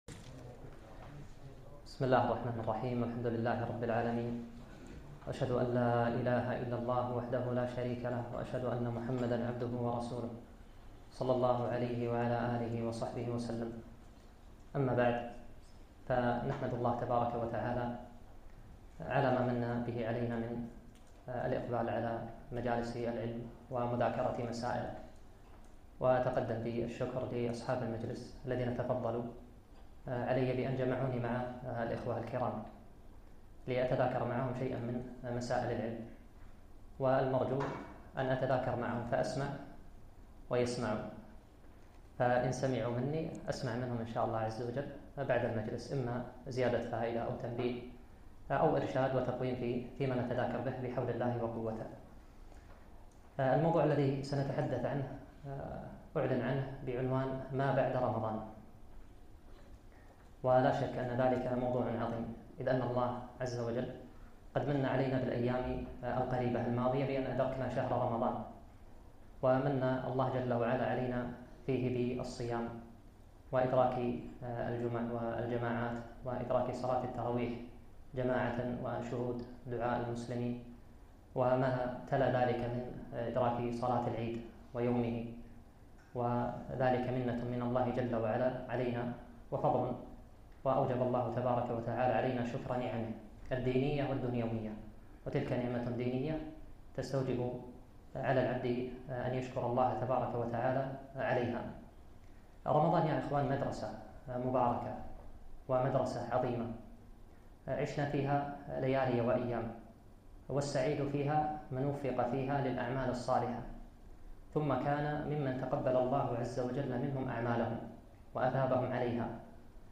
محاضرة - ماذا بعد رمضان ؟